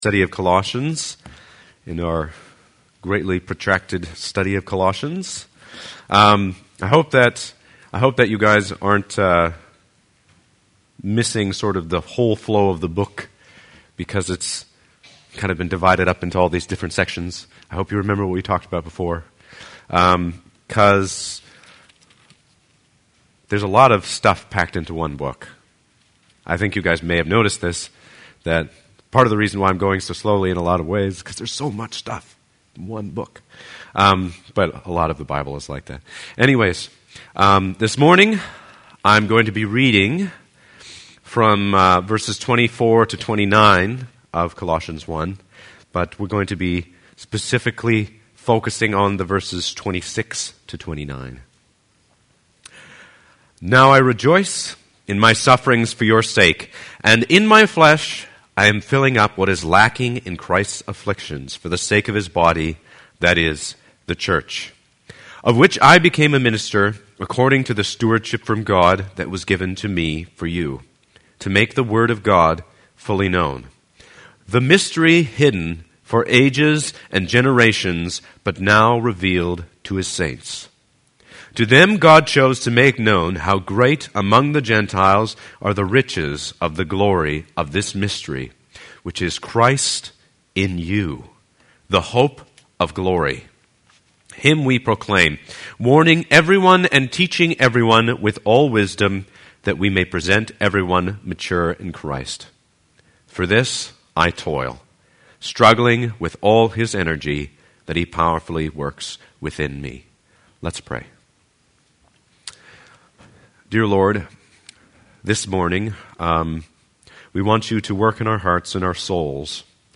Sermon Downloads: October 2013